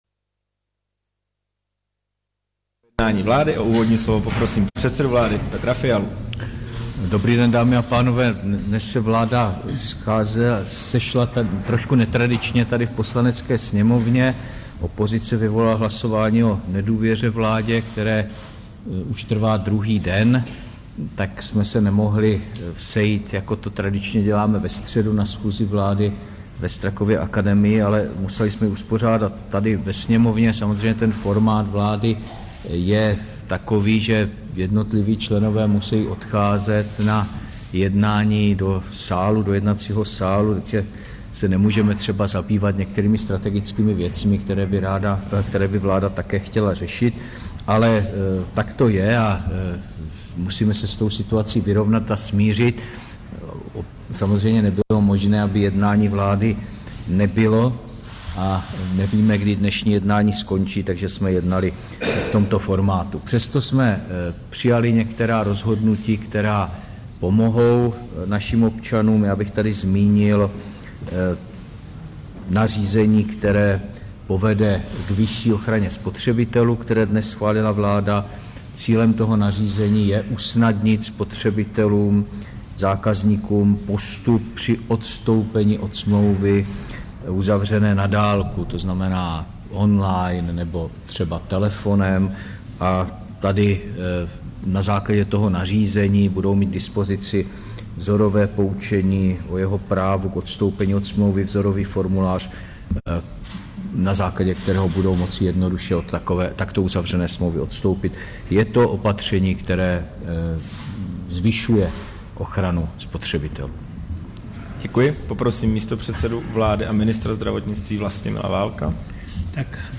Tisková konference po jednání vlády, 18. ledna 2023